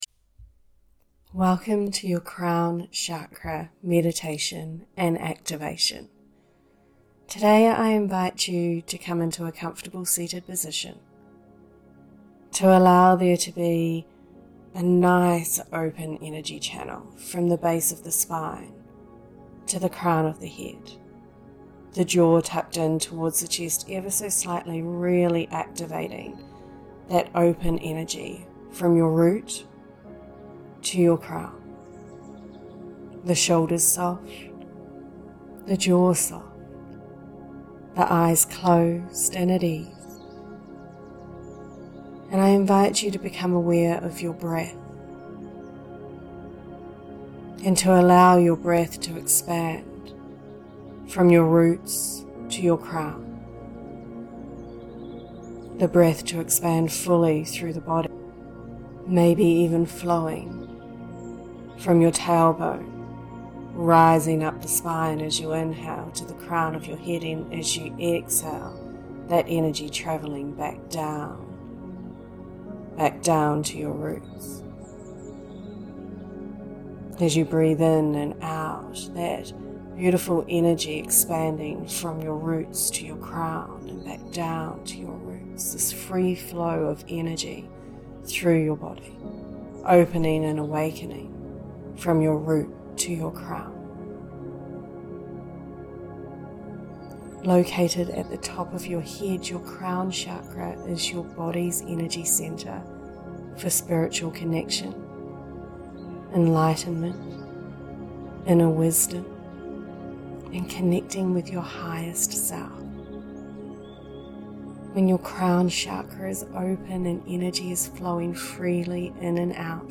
CROWN CHAKRA MEDITATION
CrownChakraActivationMeditation.mp3